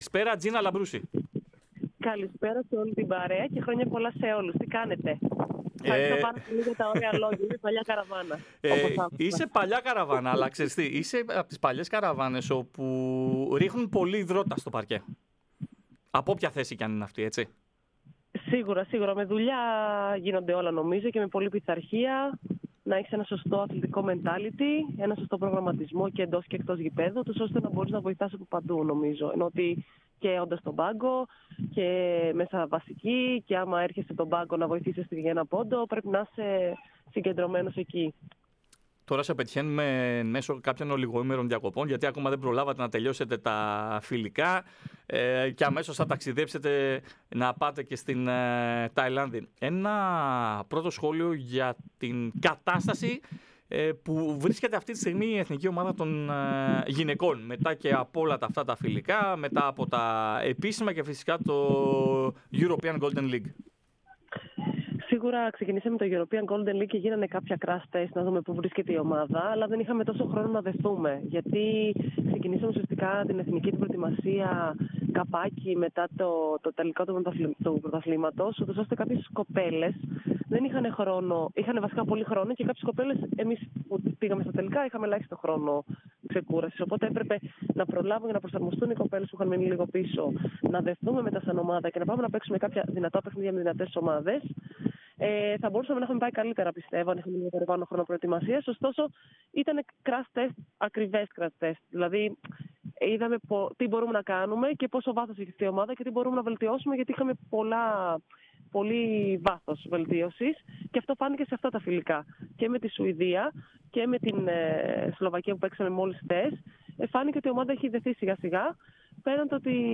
Η διεθνής κεντρική παραχώρησε συνέντευξη στην εκπομπή "Match Point" της ΕΡΑ ΣΠΟΡ, λίγες ημέρες πριν τη συμμετοχή της στο Παγκόσμιο Πρωτάθλημα βόλεϊ γυναικών.